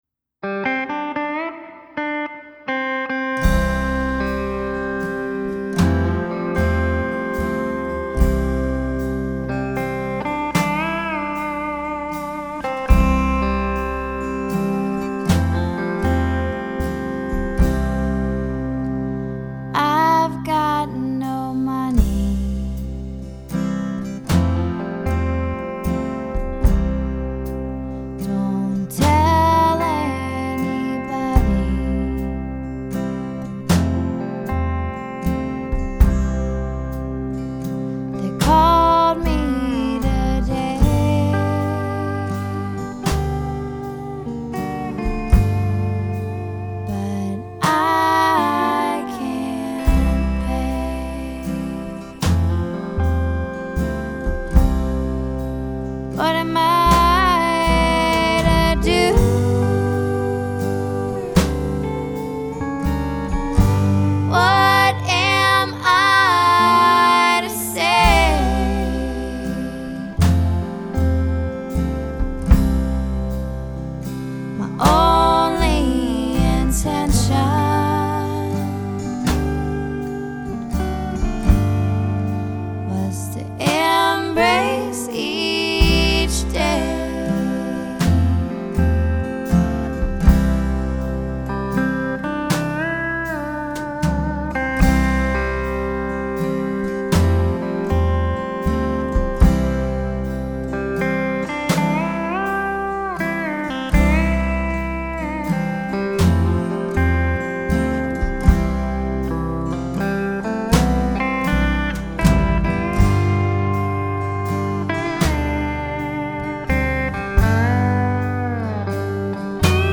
upright bass
mandolin, banjo, lap steel, guitars
drums, percussion